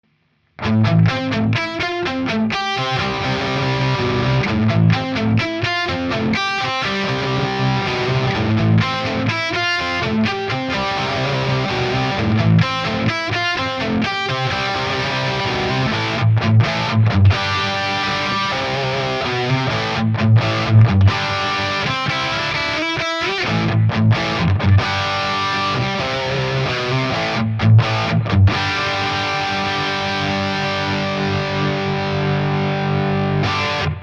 EG5 - Canal B - Test 1.mp3